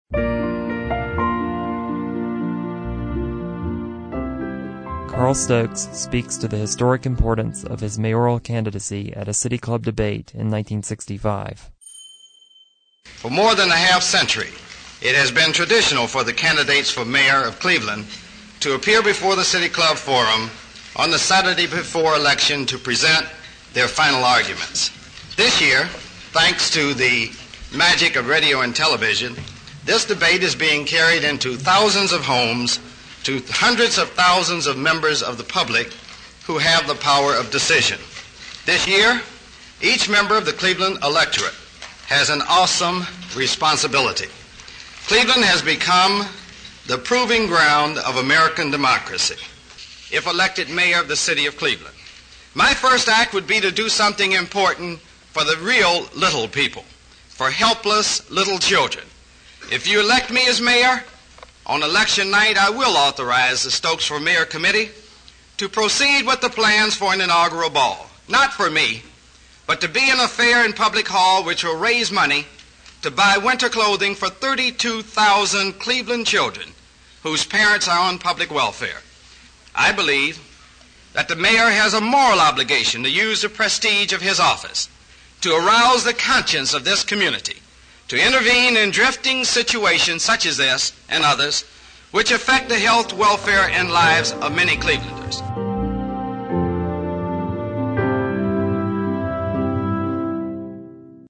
Carl Stokes speaks to the historic importance of his mayoral candidacy at a City Club debate in 1965